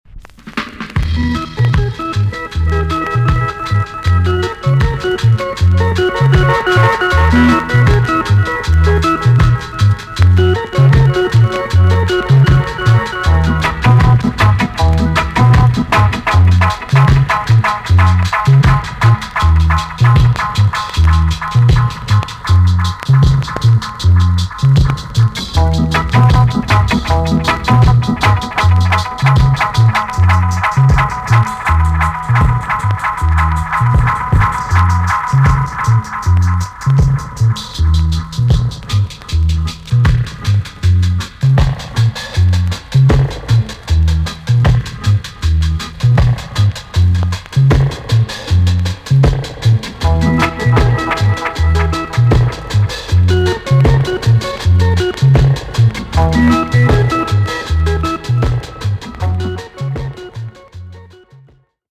B.SIDE Version
VG+ 少し軽いチリノイズがありますが良好です。